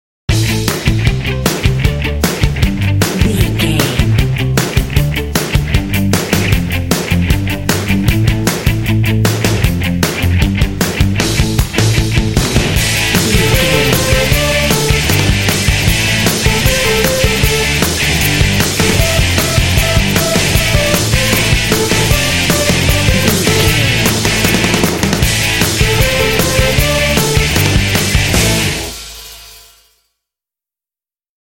This Pop track is full of energy and drive.
Ionian/Major
driving
energetic
fun
electric guitar
bass guitar
drums
electric piano
vocals
pop